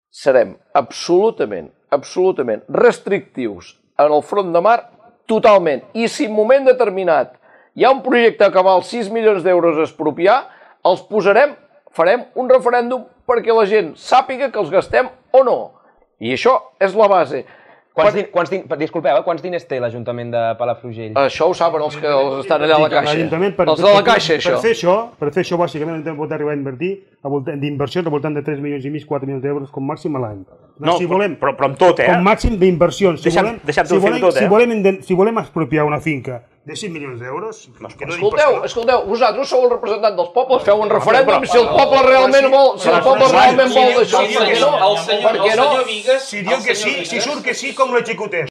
Debat Electoral Palafrugell 2019